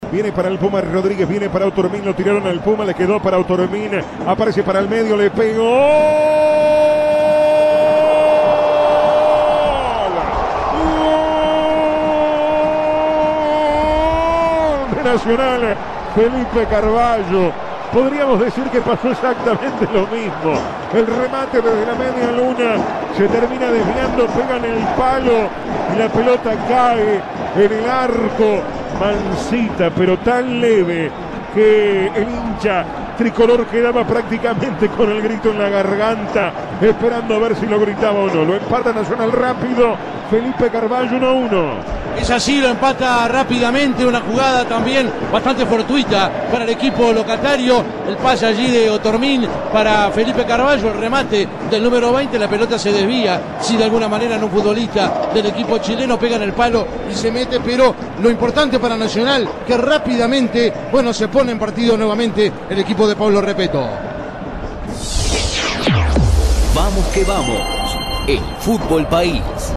Relato Vamos que vamos: